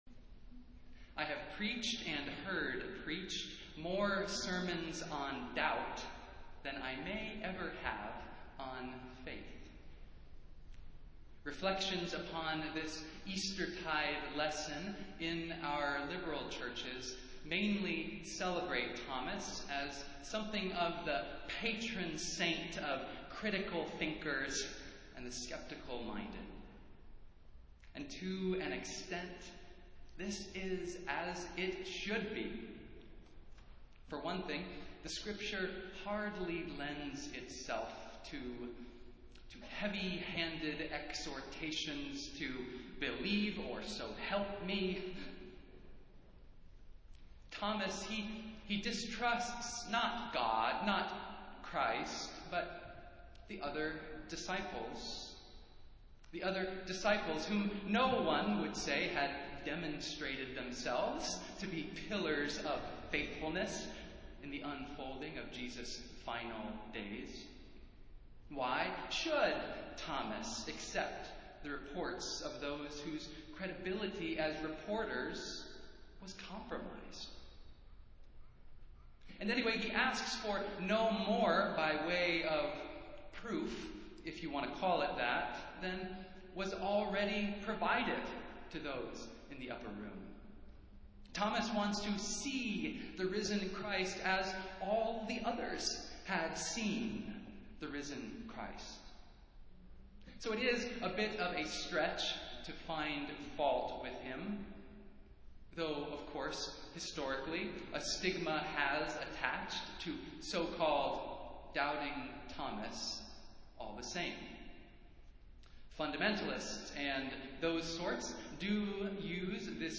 Festival Worship - Second Sunday of Easter